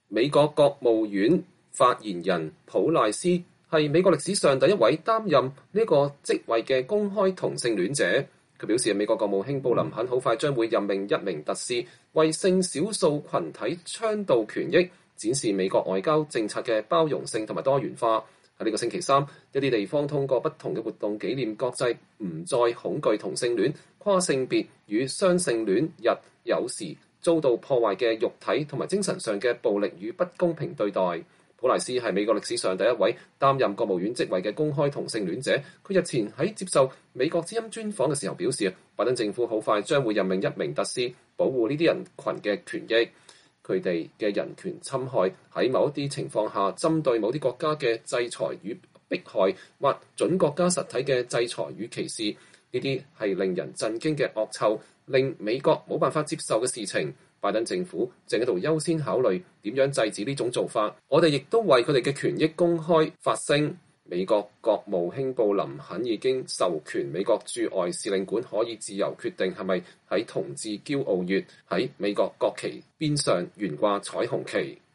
普賴斯是美國歷史上第一位擔任國務院職位的公開同性戀者，他日前在接受美國之音專訪的時候表示，拜登政府很快將任命一名特使，保護這些人群的權益。